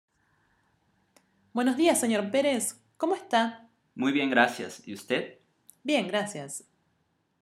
diálogo formal, Ex 3, p3